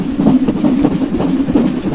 pumpkin_high.ogg